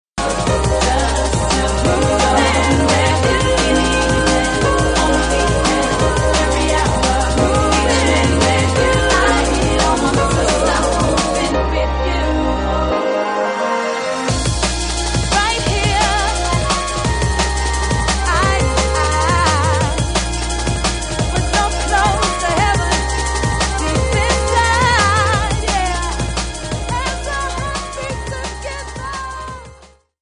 TOP > Vocal Track
TOP > Deep / Liquid